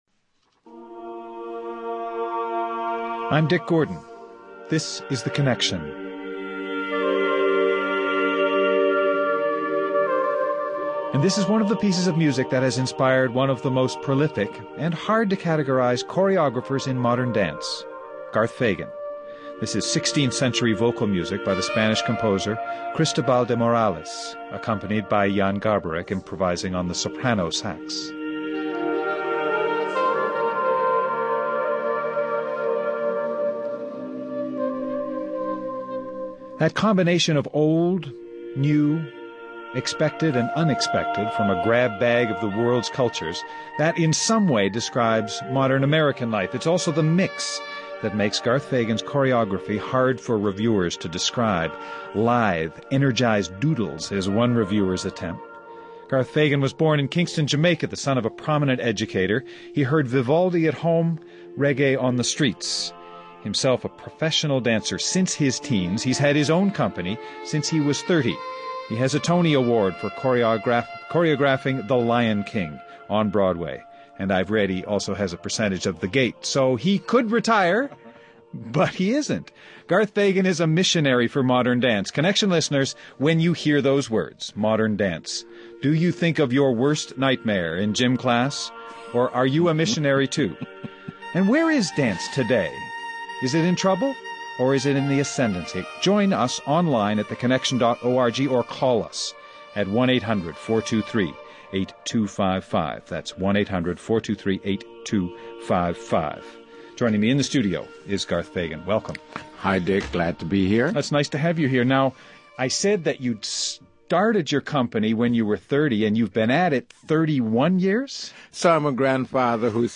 Jamaican-born Garth Fagan has been leading Guests: Garth Fagan, founder and artistic director, Garth Fagan Dance.